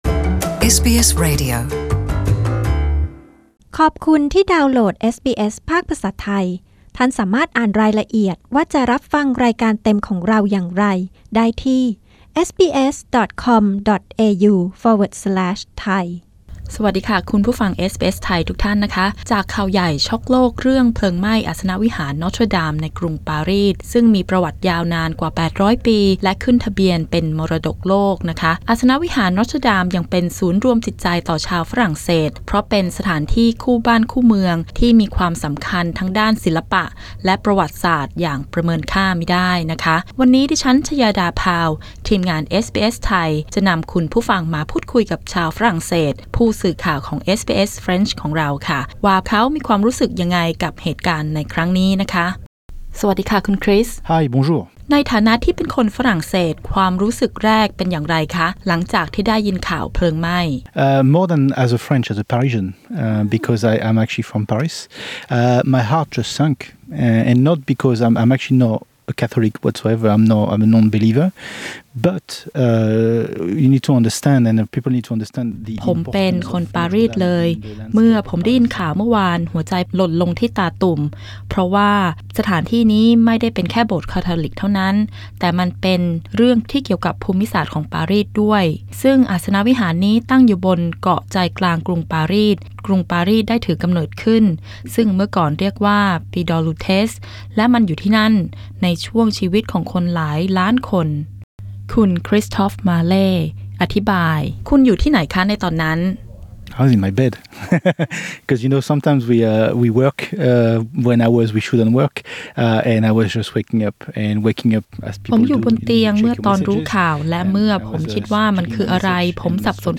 ที่ห้องส่งสำนักงานนครเมลเบิร์น